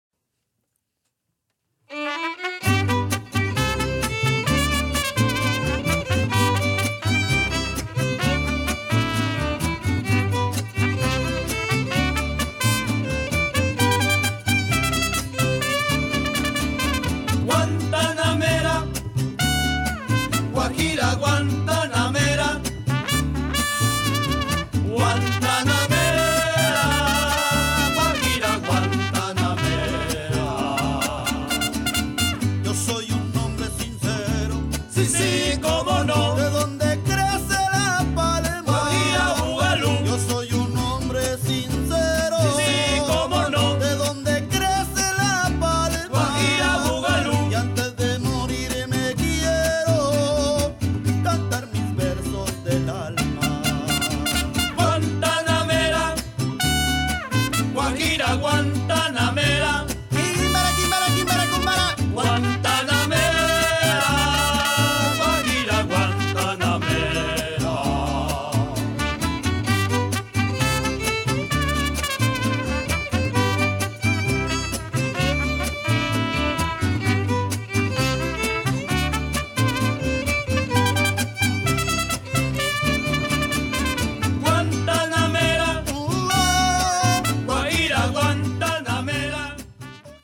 This New York Mariachi group was created to reflect the vision of an inspiring, artistic mariachi ensemble.
They play from the traditional Mexican music to the most recent waves of styles.